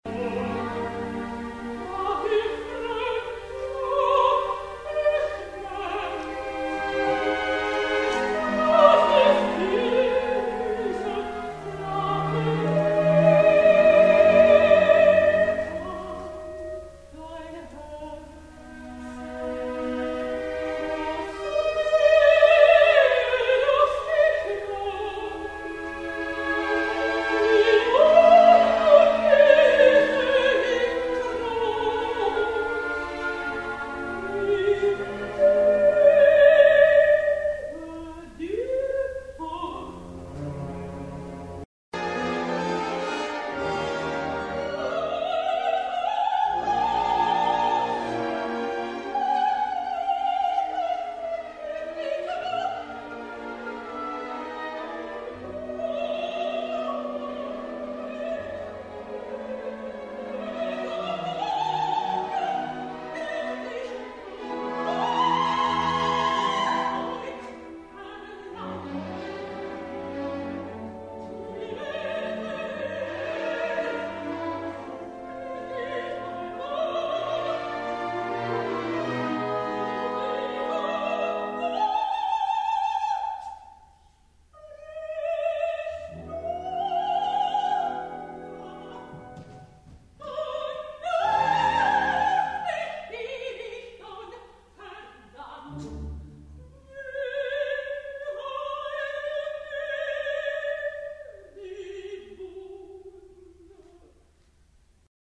Operns�ngerin, Mezzosopran